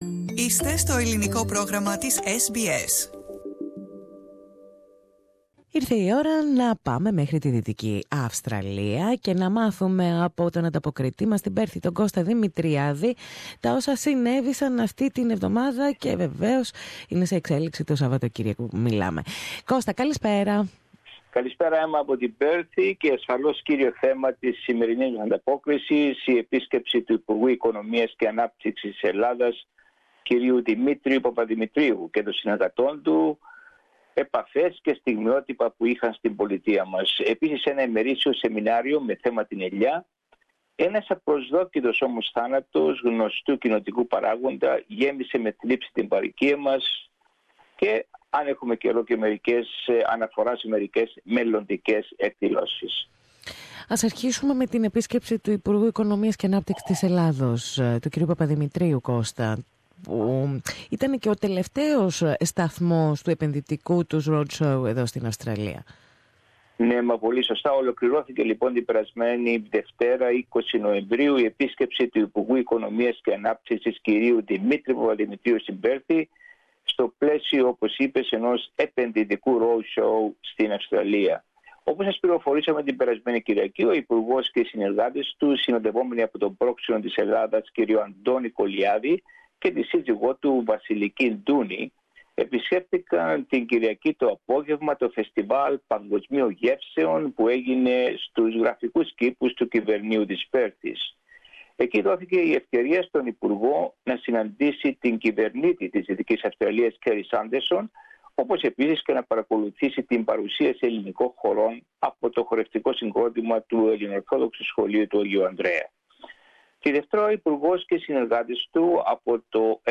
Weekly report from Perth, Western Australia